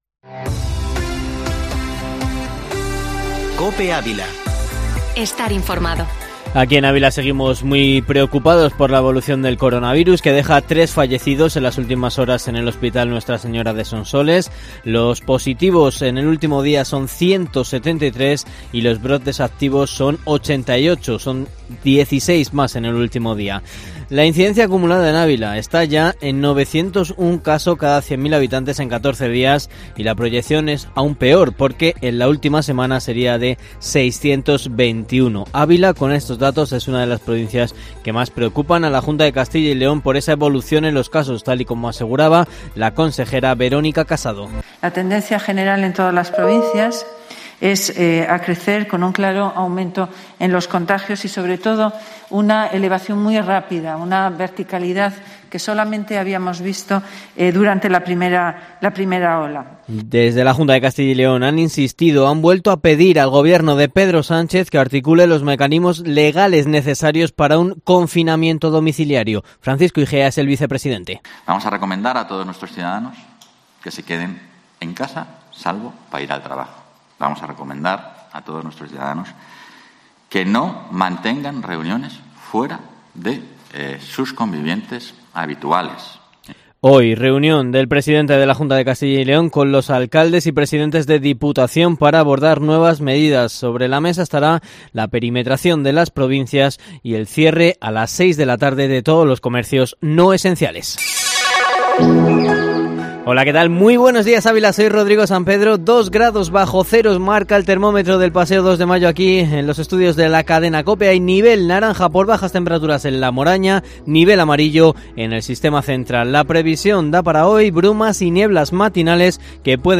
Informativo matinal Herrera en COPE Ávila 15/01/2021